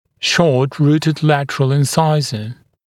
[ˌʃɔːt’ruːtɪd ‘lætərəl ɪn’saɪzə][ˌшо:т’ру:тид ‘лэтэрэл ин’сайзэ]боковой резец с укороченным корнем